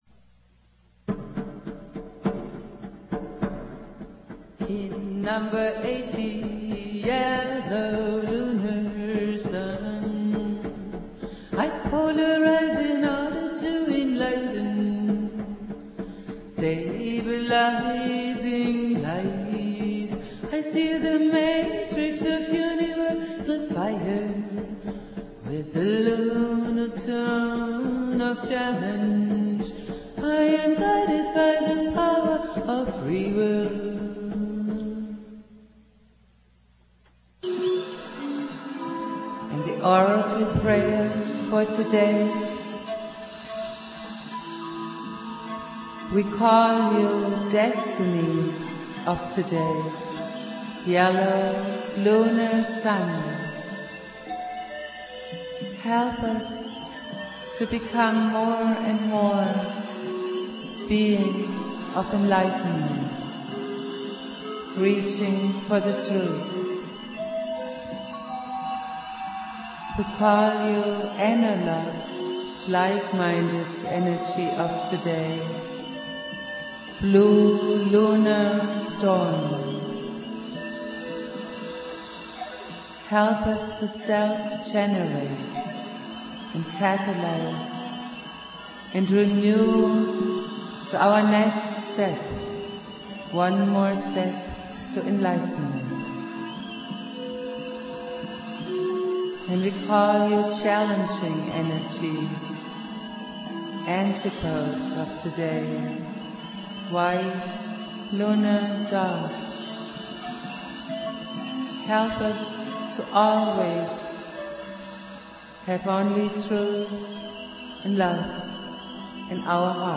Prayer
playing flute
produced at High Flowing Recording Studio
Jose's spirit and teachings go on Jose Argüelles playing flute.